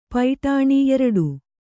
♪ paiṭāṇi